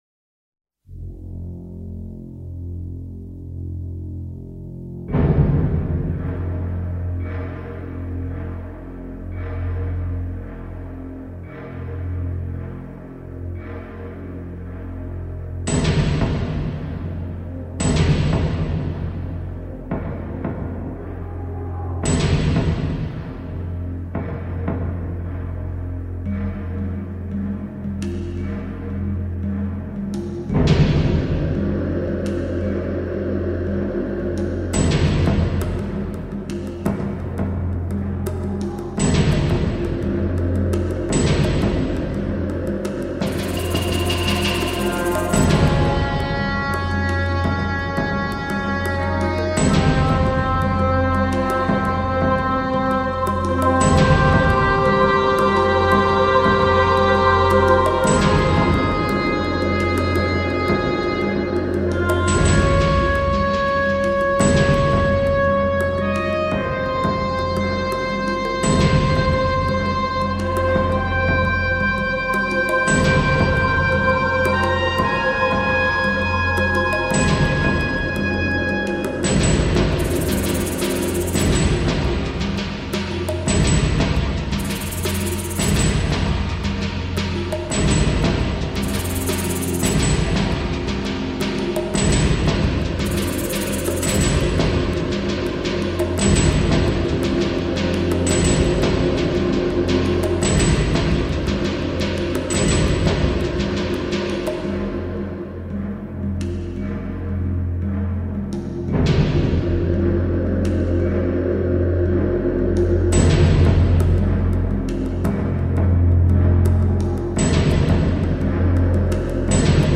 synthétique et atmosphérique